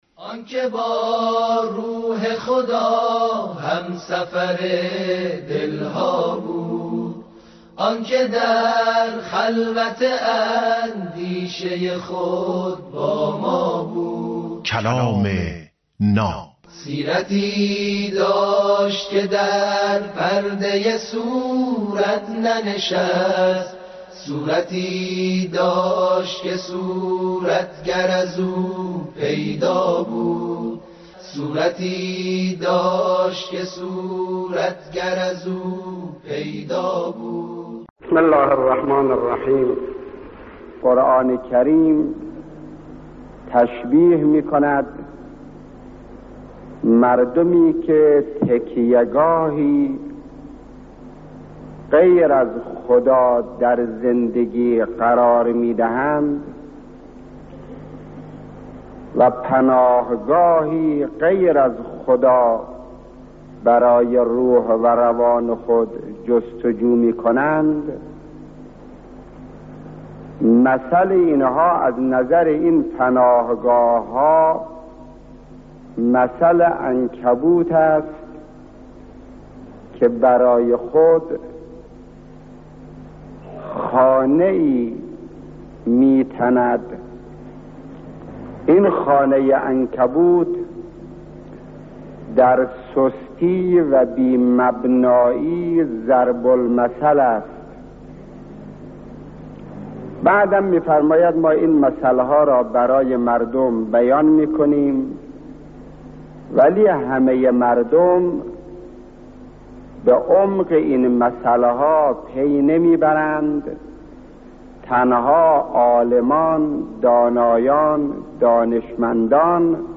کلام ناب برنامه ای از سخنان بزرگان است